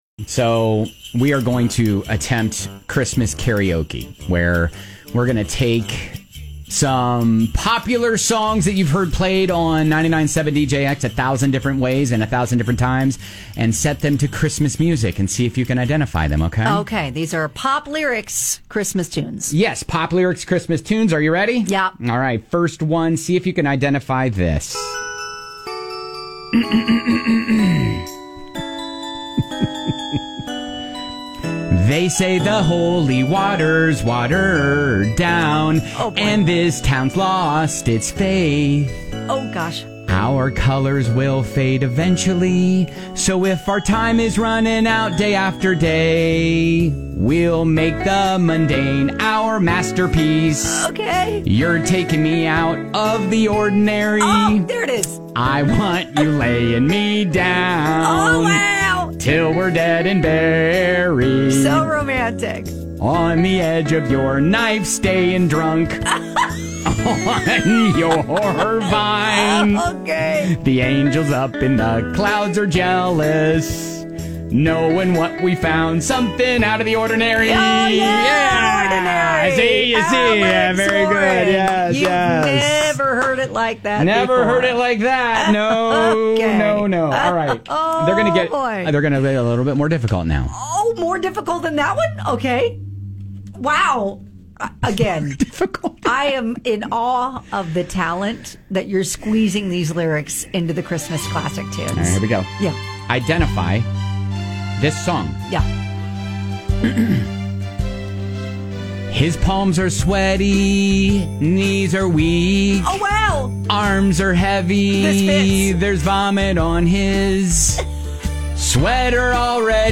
We take the music of your favorite Christmas songs and sing the lyrics of your favorite pop songs!